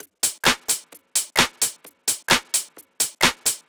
Drum Loops 130bpm